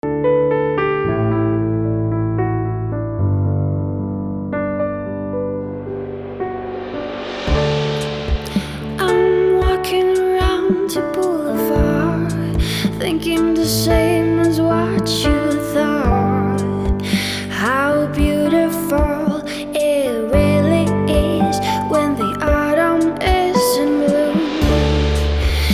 a Macedonian pop rock indie jazz folk supergroup
Lead Vocal
Bass
Saxophone
Drums